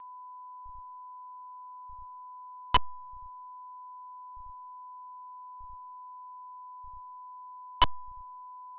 将 CLK WS 电流配置为8mA、  PCM6260 CH1在 使用 A2B 模块时显示砰砰声。